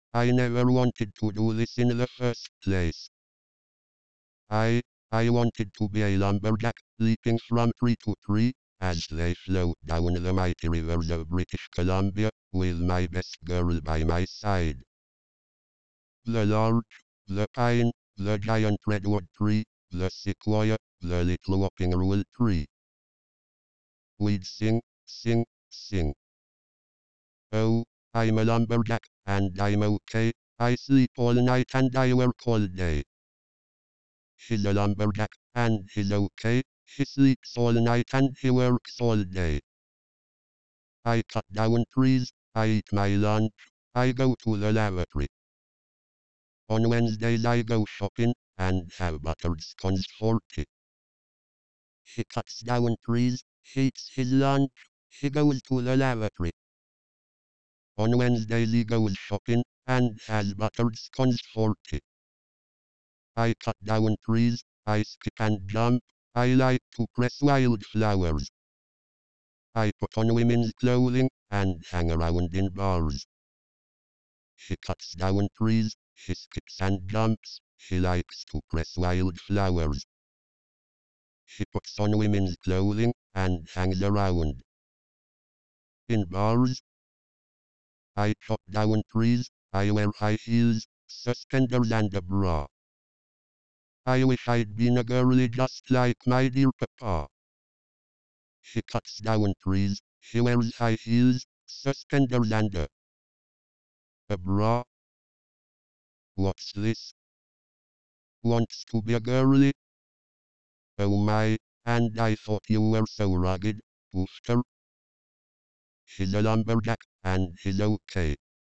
“The Lumberjack song#8221; as a sample of synthetic speech produced by the Gnuspeech articulatory text-to-speech system
The Lumberjack Song—spoken, not sung (Monty Python)
1. the words are spoken, rather than sung;
This synthesis represents the current state of the Gnuspeech text-to-speech system.